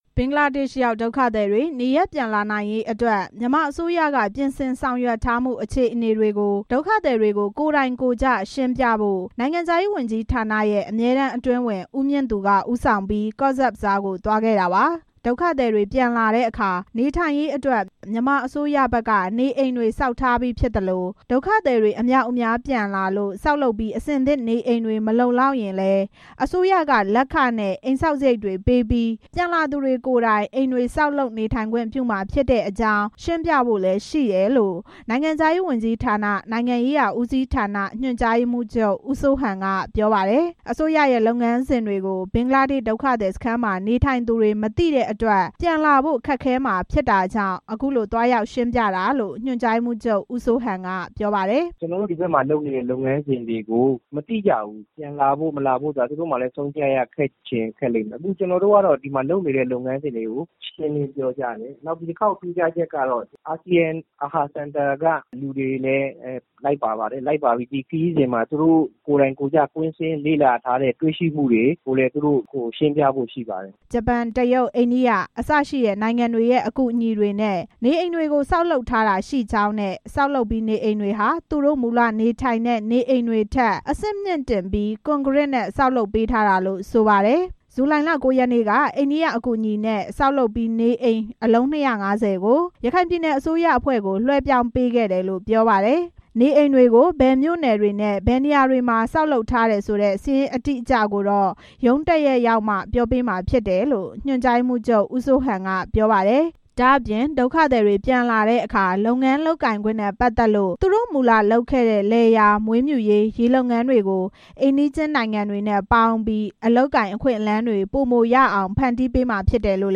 ကော့ဆက်ဘဇားက ဒုက္ခသည်စခန်းအတွင်း နေထိုင်ကြသူ တချို့ကို RFA က ဒီနေ့ ဖုန်းနဲ့ ဆက်သွယ်ခဲ့ရာမှာ မြန်မာအစိုးရကိုယ်စားလှယ်အဖွဲ့နဲ့ တိုက်ရိုက်တွေ့ခွင့်ရခဲ့သူတွေကိုတော့ အဆက်အသွယ်မရခဲ့ပါဘူး။